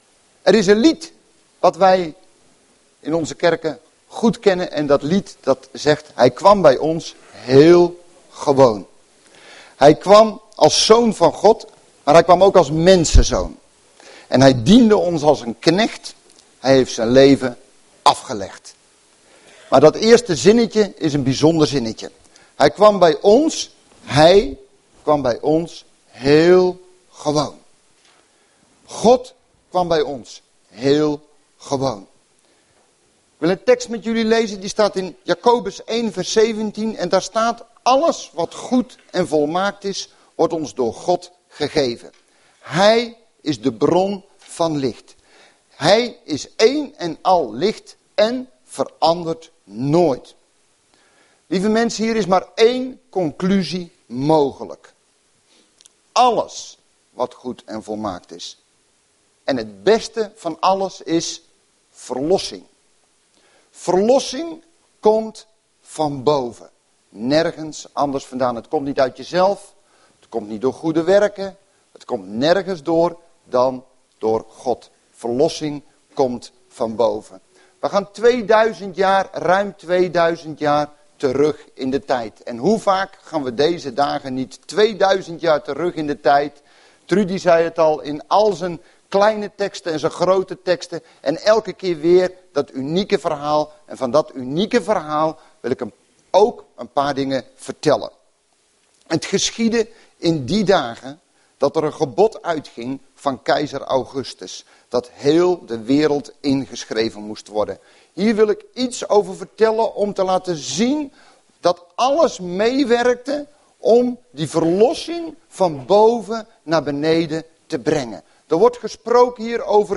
Overzicht van preken van Preken op Christengemeente Bethel
Kerstoverdenking